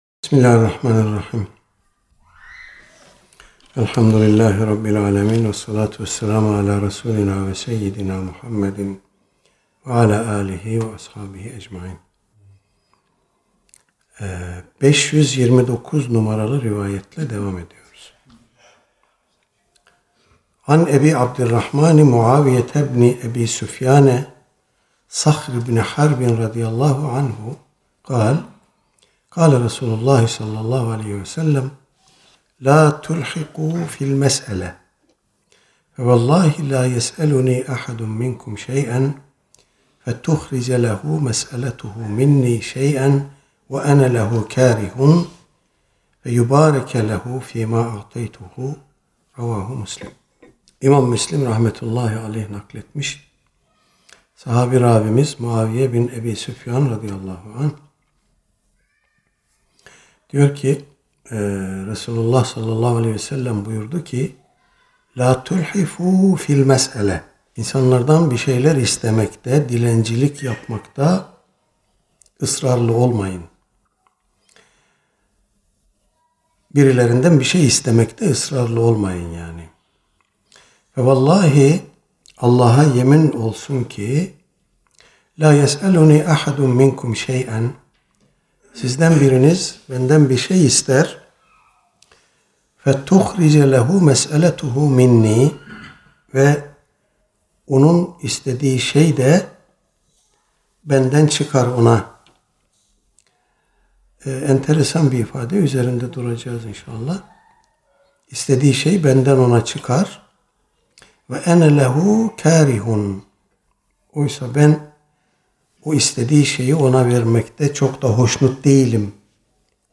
Seminer yeri: Uluçınar Vakfı – Pendik.